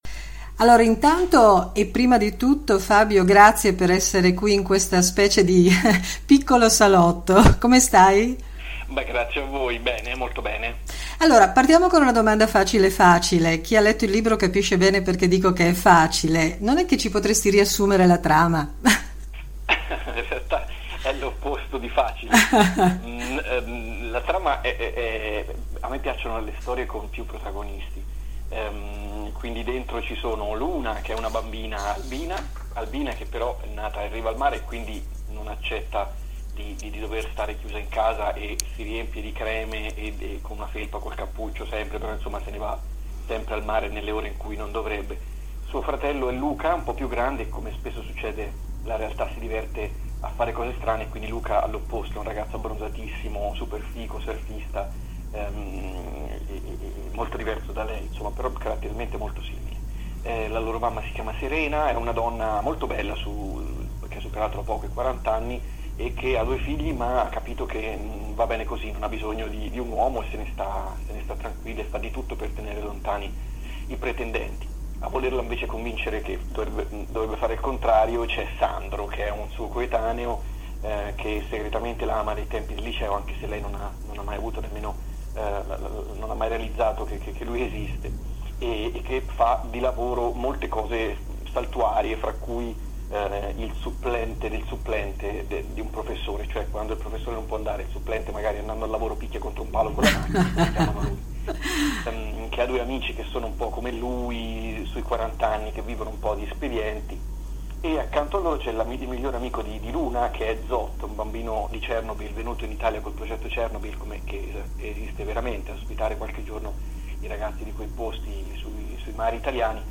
“Chi manda le onde”: chiacchierata con Fabio Genovesi